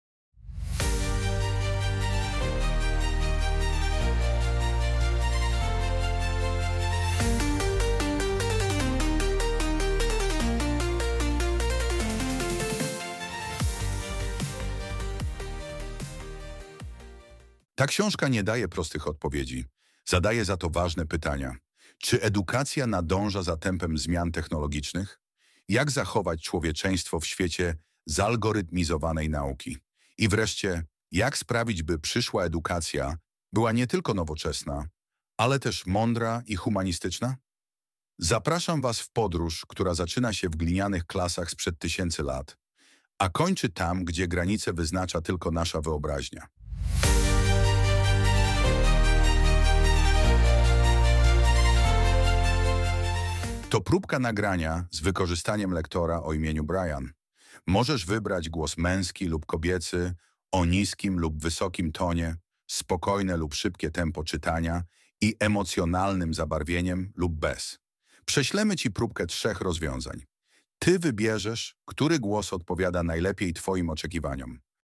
Nagrywanie audiobooków z wykorzystaniem inteligentnego oprogramowania to nowoczesny sposób tworzenia nagrań głosowych, w którym cała narracja powstaje bez udziału tradycyjnego lektora, za to z użyciem sztucznie wygenerowanego głosu o wysokiej jakości.
Posłuchaj próbek z muzycznym intro do audiobooka: